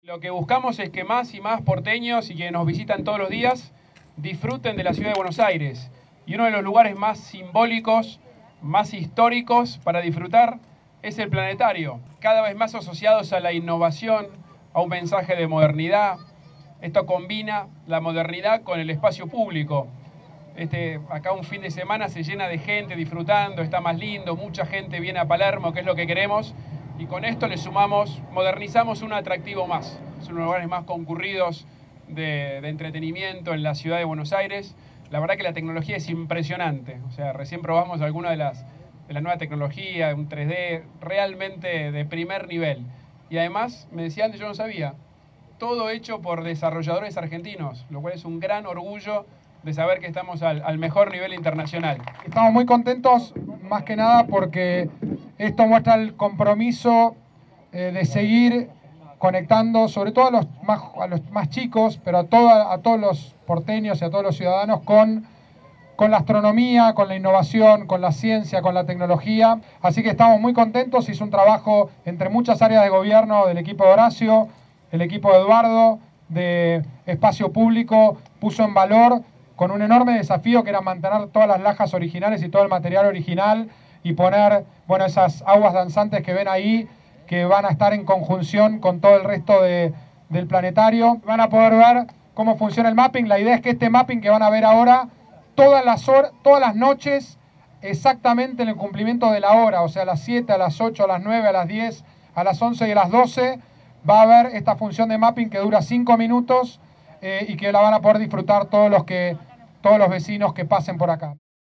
Rodríguez Larreta participó de la reinauguración del Planetario